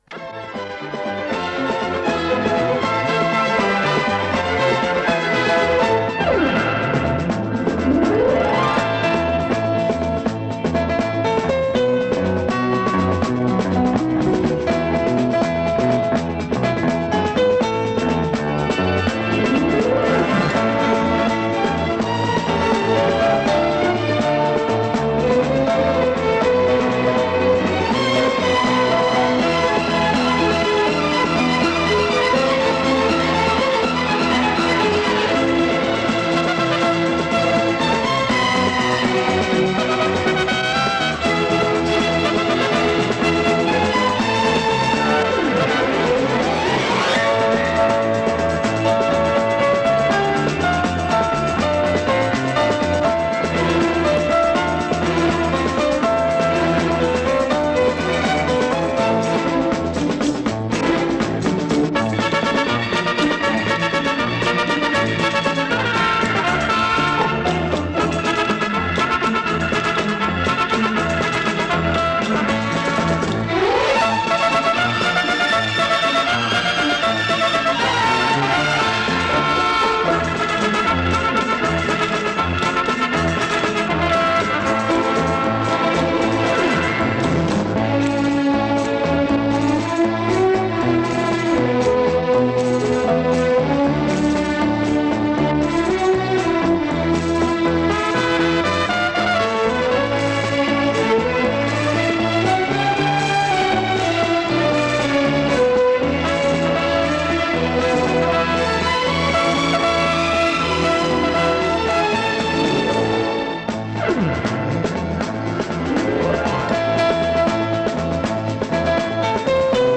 Может быть кто-то сумеет опознать эту симпатичную пьесу для оркестра?
Аранжировка какая то динамичная, известной композиции.
Партия духовых смущает.